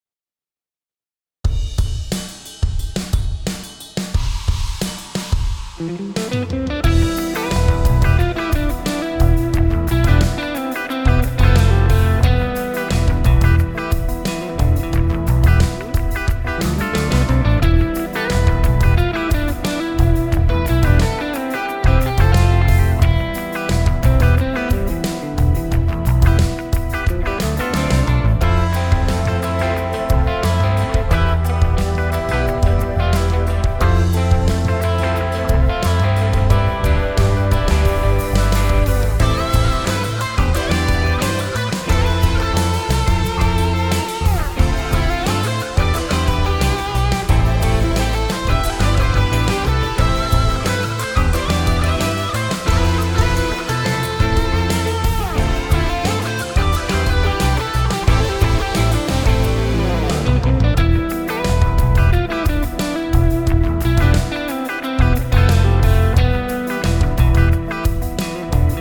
For my demonstration, I've written a piece that moves between E Phrygian and A minor (aka A Aeolian). You'll hear the E Phrygian sound at the beginning, then the tonality drifts away from that solid E minor base and becomes ambiguous before things land right into the key of A minor.
phrygian-to-aeolian.mp3